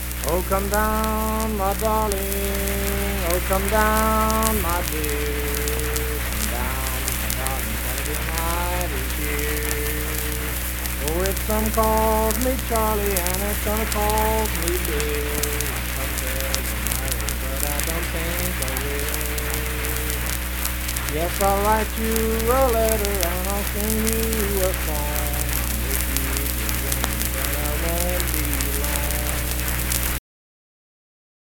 O Come Down My Darling - West Virginia Folk Music | WVU Libraries
Unaccompanied vocal music
Voice (sung)
Pleasants County (W. Va.), Saint Marys (W. Va.)